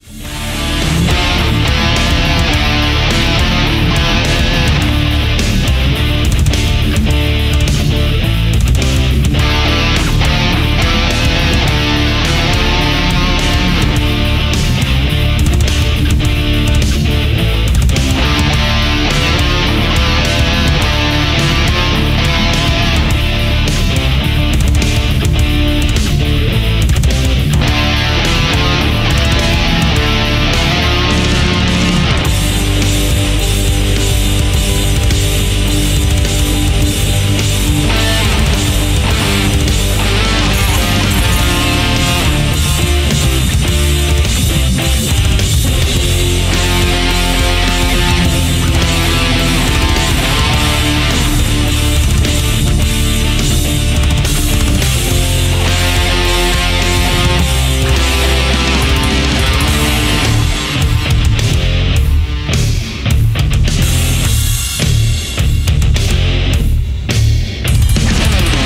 Metal Cover